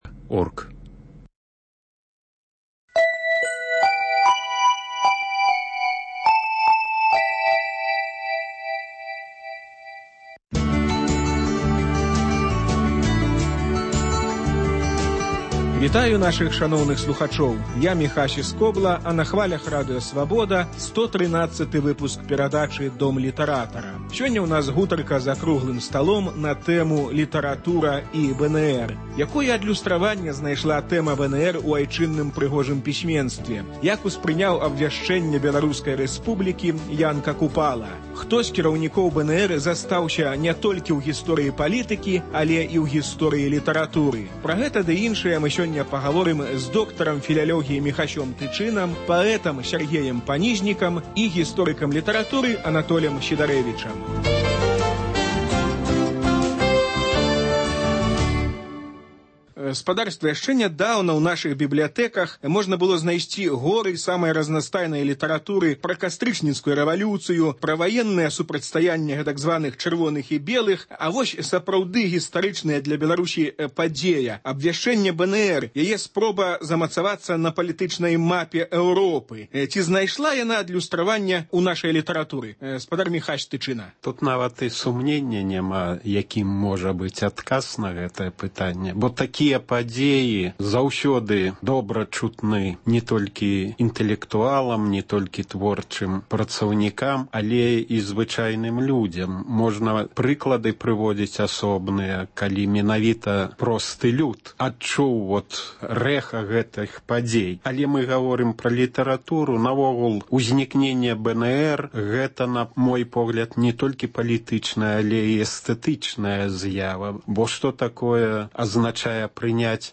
Круглы стол на тэму "Літаратура і БНР"